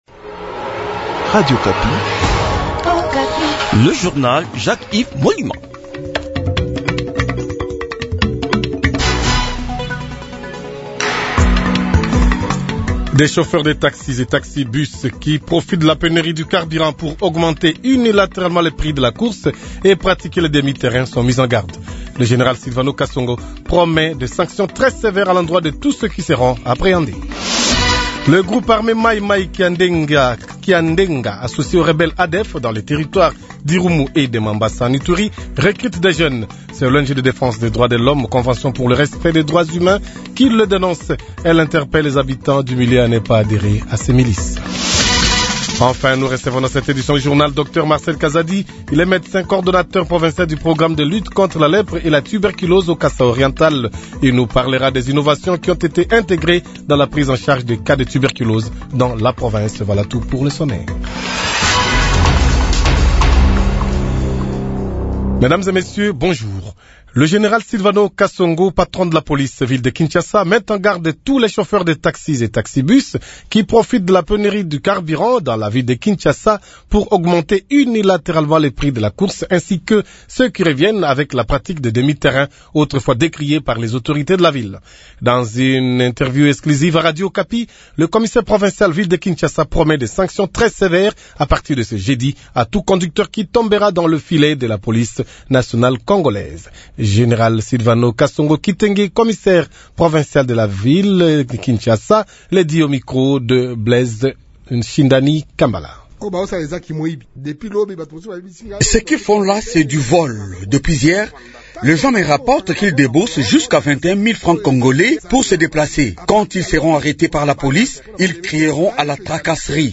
Journal Midi
Kinshasa ; reportage sur le phénomène demi – terrain